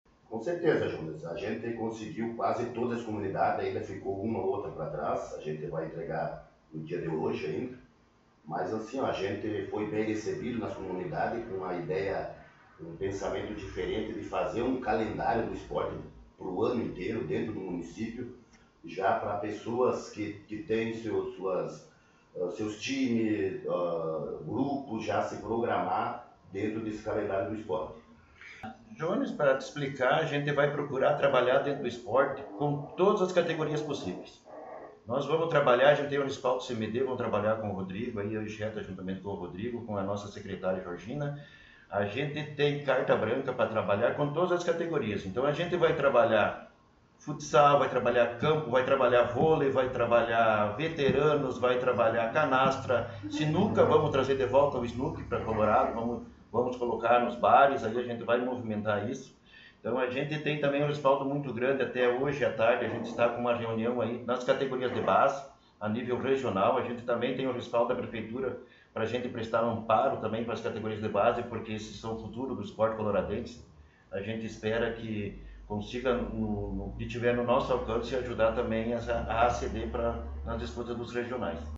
Coordenadores do Esporte Municipal concederam entrevista